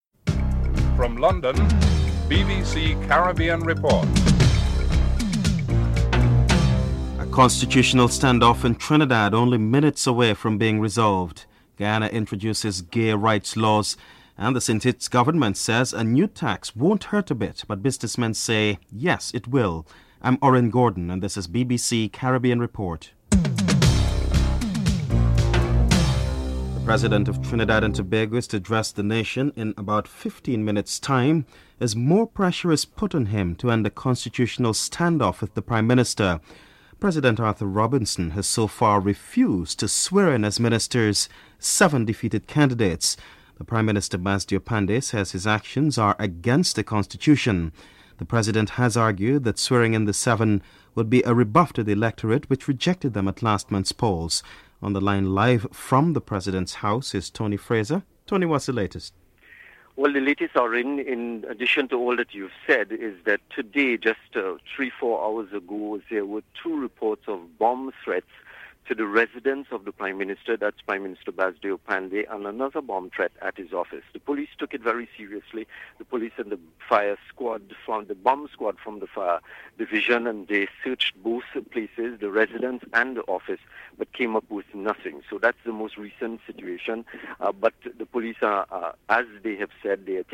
1. Headlines (00:00-00:25)
Cricketer Carl Hooper is interviewed